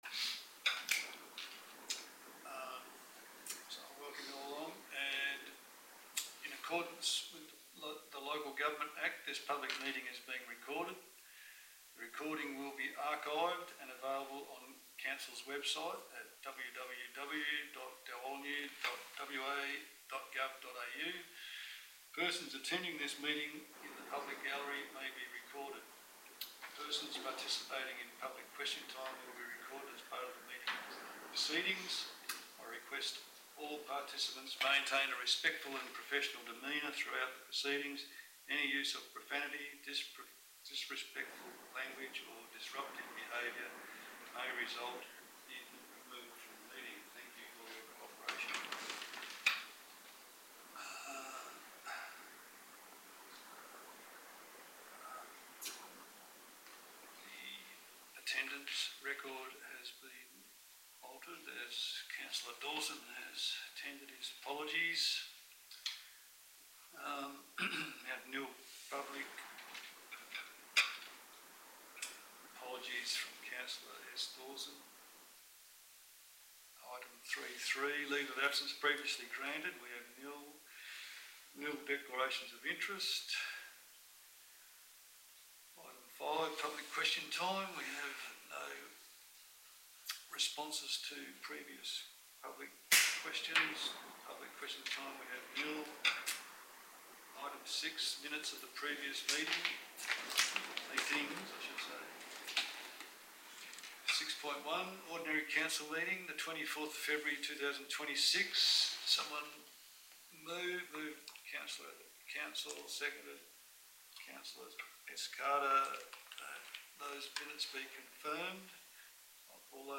Ordinary Council Meeting - 24 March 2026 » Shire of Dalwallinu